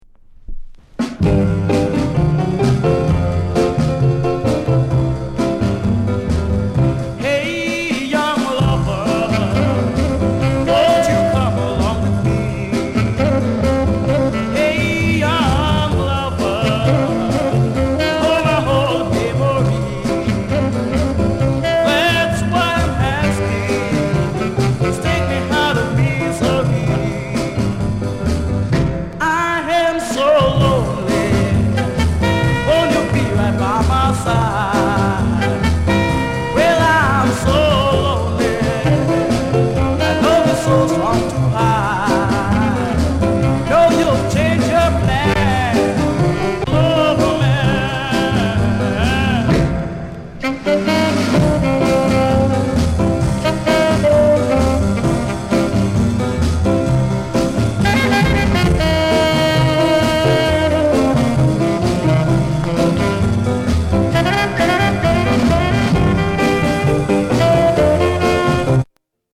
SHUFFLE TUNE